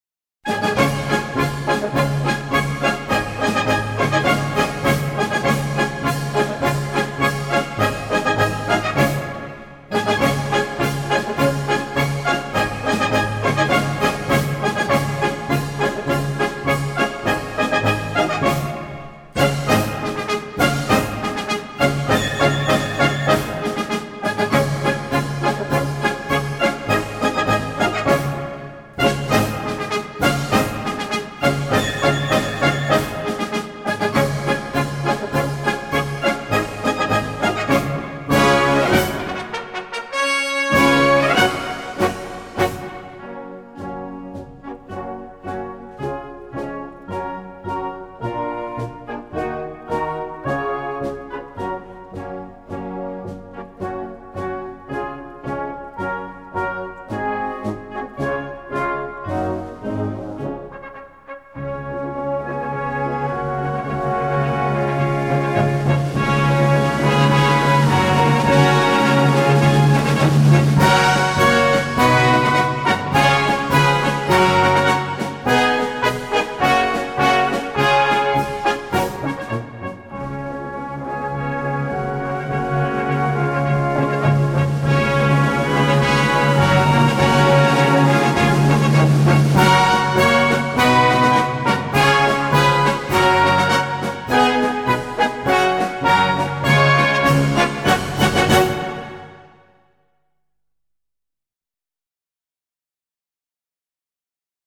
Marches Prussiennes